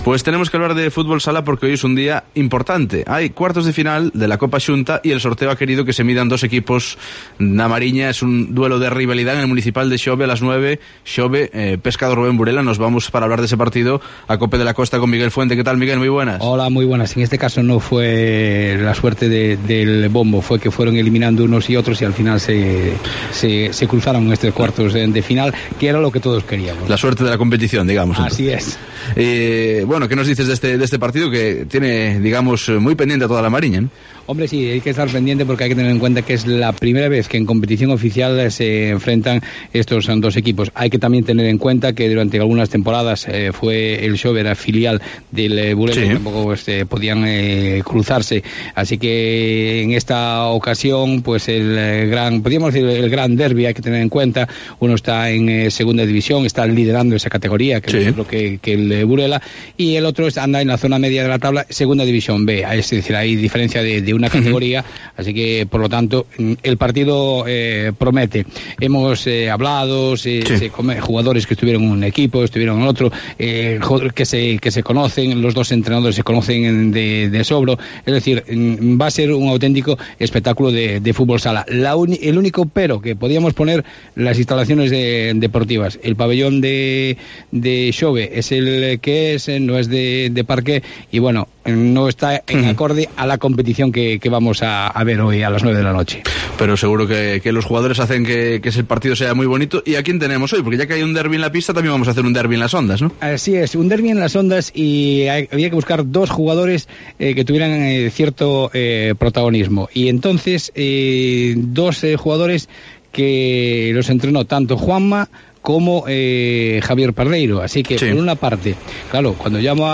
AUDIO: Los jugadores y entrenadores de Burela y Xove han hablado del partido de cuartos de Copa Galicia, un derby de la Mariña